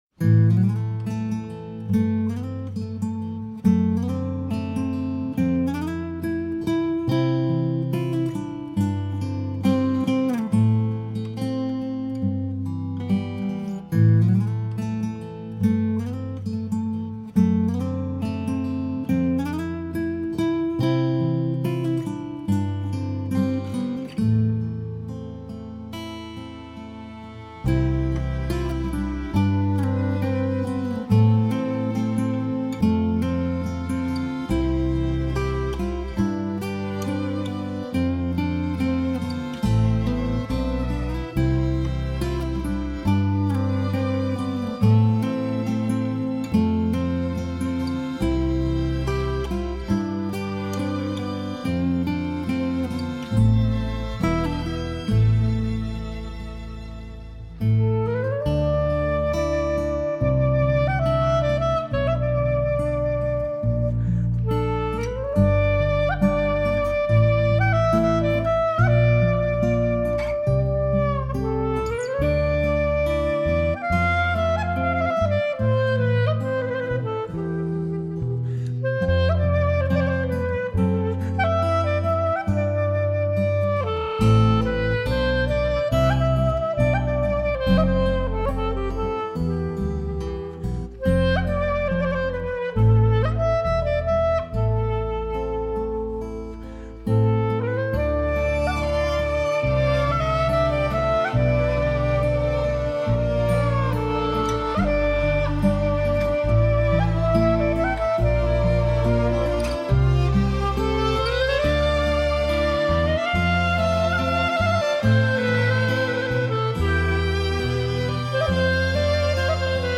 הניגונים מוגשים בביצועיים אקוסטיים מיוחדים ומרגשים
בביצועיים אקוסטיים מיוחדים ומרגשים.